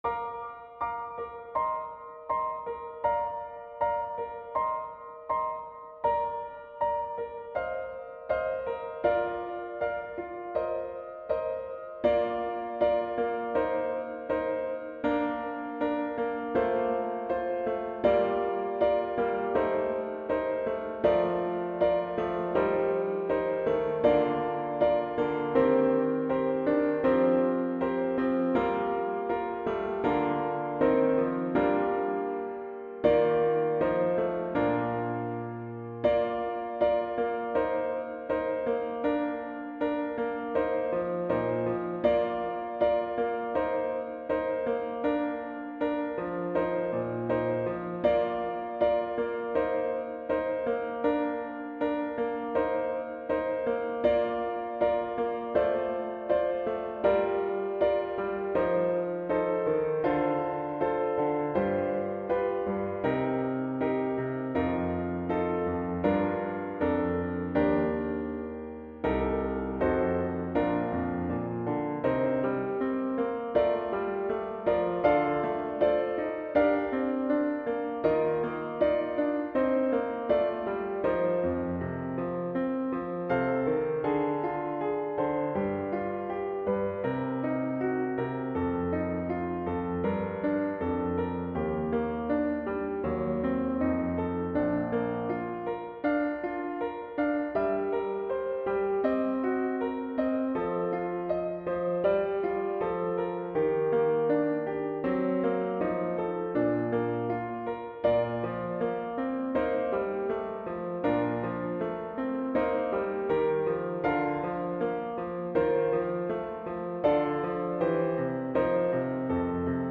für 3× Klarinette in B und Klavier
Playalong - 1.